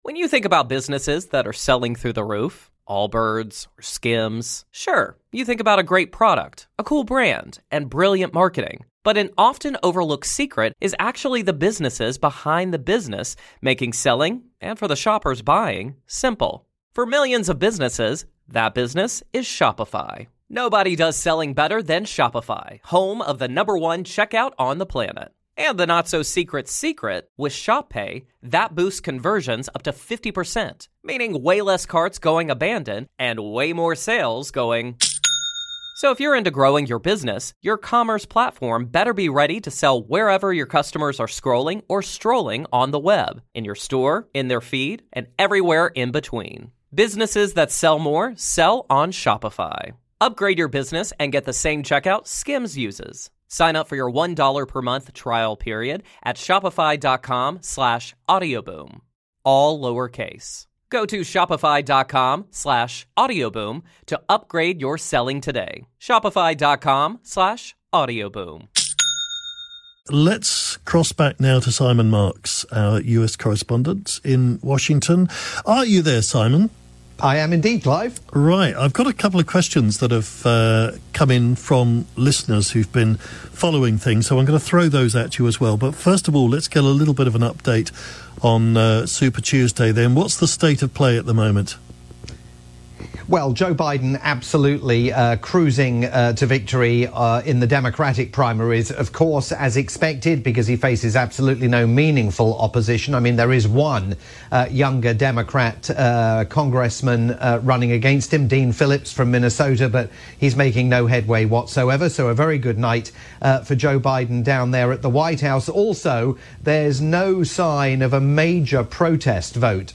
live update
overnight programme on LBC, including some Q&A with listeners.